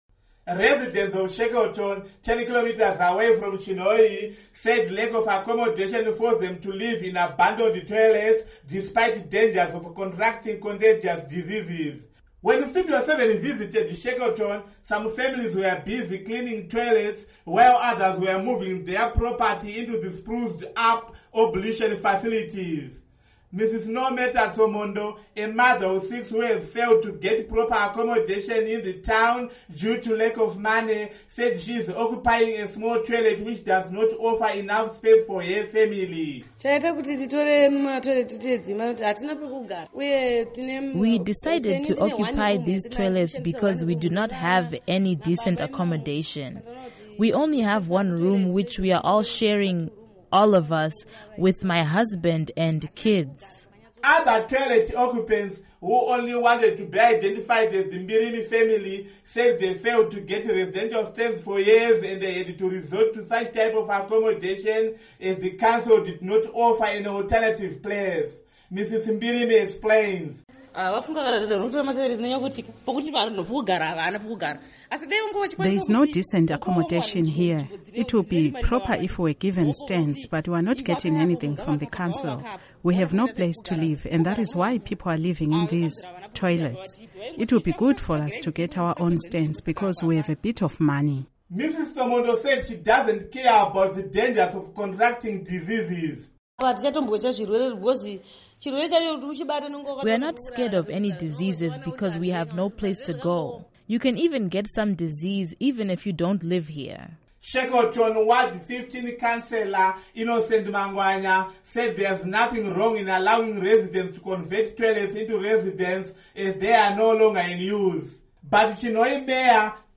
Report on Accommodation Blues in Chinhoyi